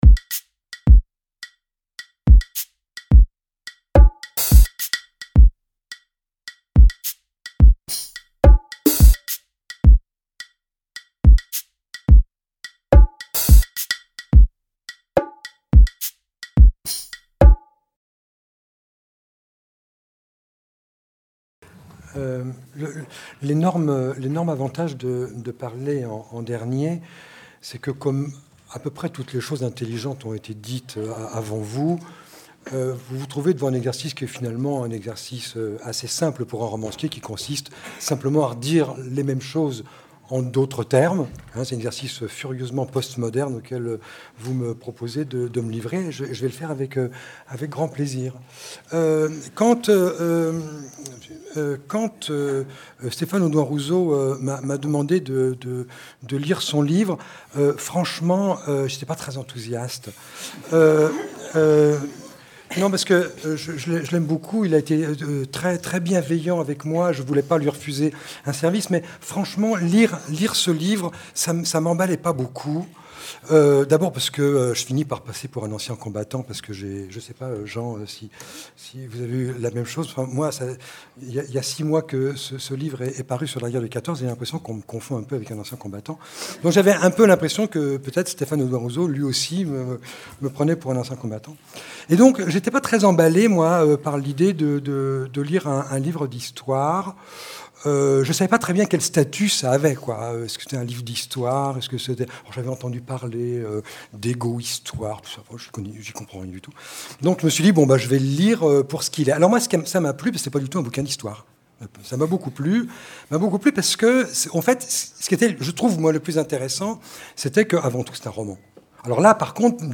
Littérature, histoire Débat organisé par les Éditions de l'EHESS et les Cercles de formation de l'EHESS Débat autour du livre de Stéphane Audoin-Rouzeau Quelle histoire ? Un récit de filiation (1914-2014).